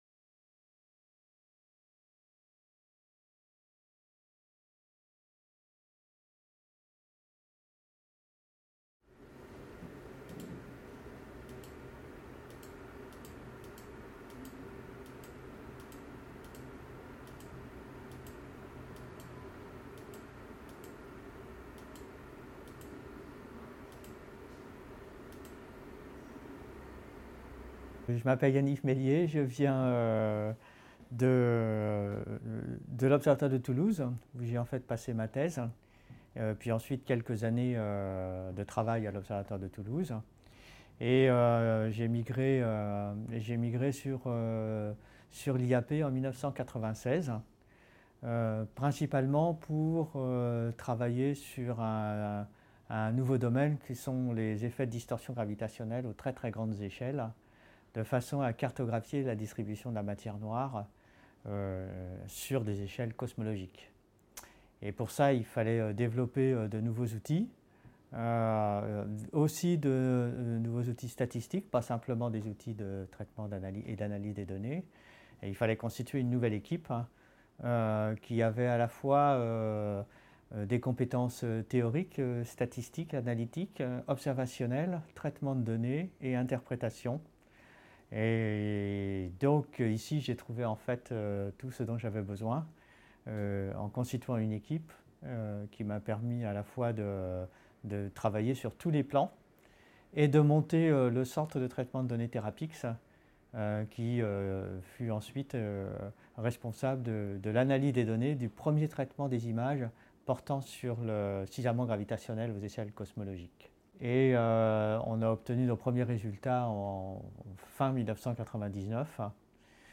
Video Interview